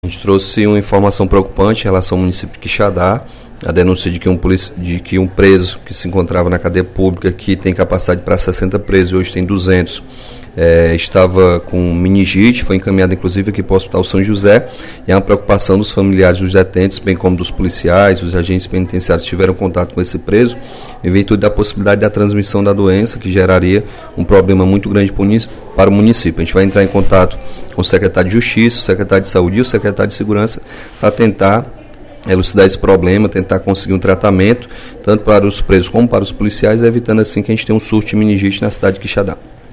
O deputado Capitão Wagner (PR) denunciou, em pronunciamento no primeiro expediente da sessão plenária desta quarta-feira (24/06), que um preso da cadeia pública de Quixadá foi diagnosticado com meningite.